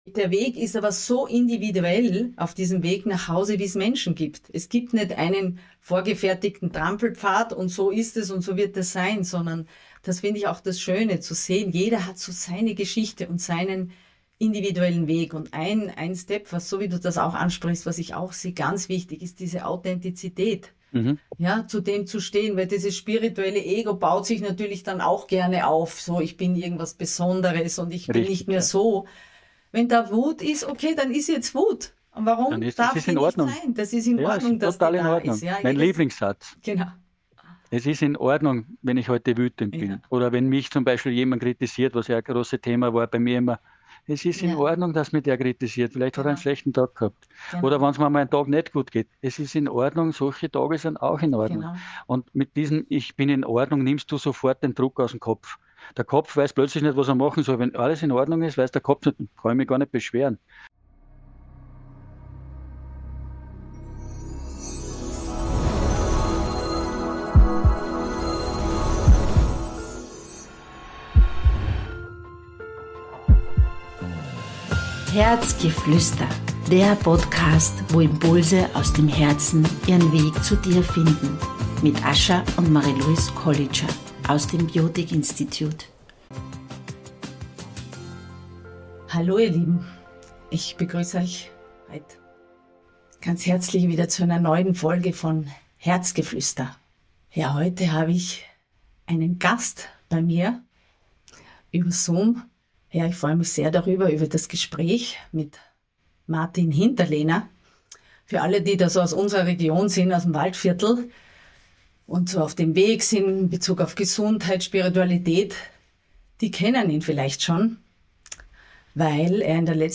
Ein Dialog über Menschsein, Wachstum und die Einladung, dem eigenen inneren Ruf zu folgen.